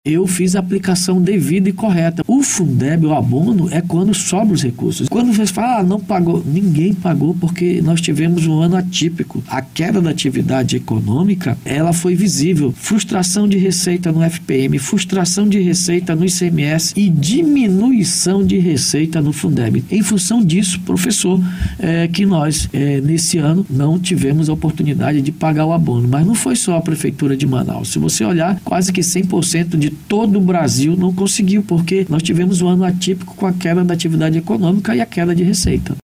A afirmação ocorreu durante entrevista na BandNews Difusora nessa quarta-feira, 03.